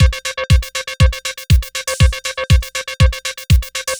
NRG 4 On The Floor 027.wav